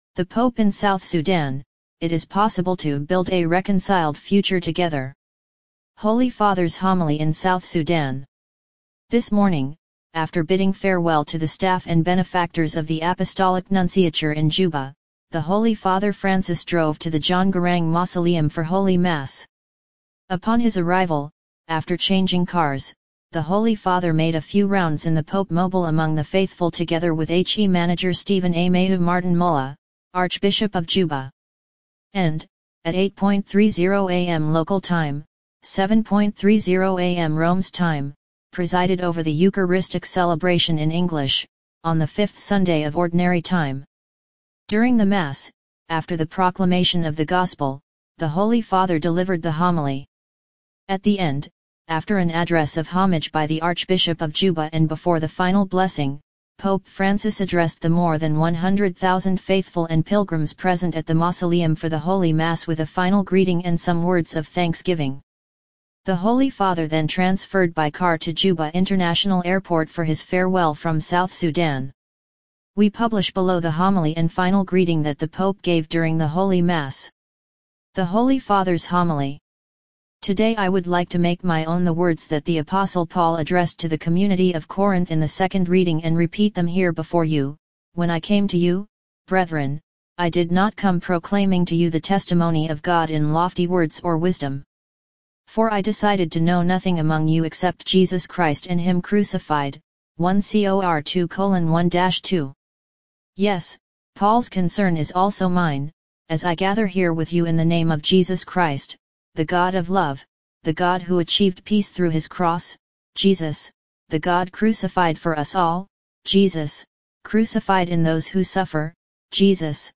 Holy Father's homily in South Sudan
During the Mass, after the proclamation of the Gospel, the Holy Father delivered the homily.